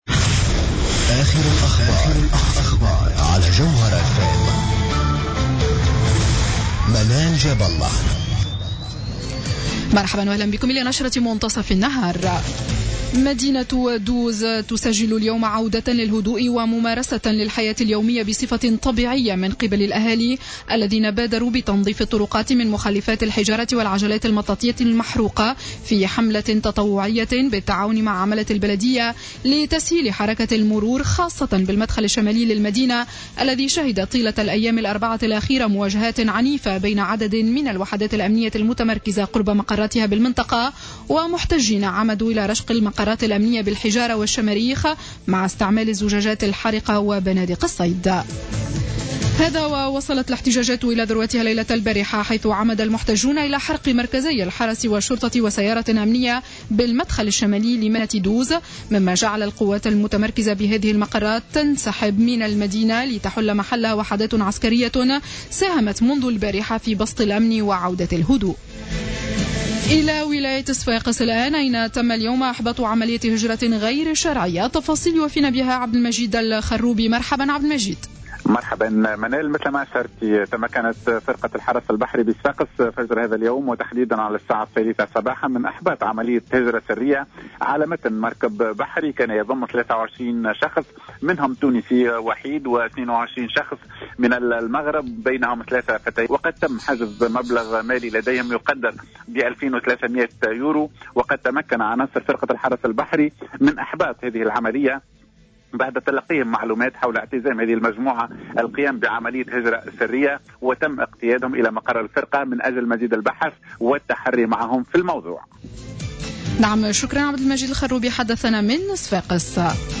نشرة أخبار منتصف النهار ليوم السبت 06 جوان 2015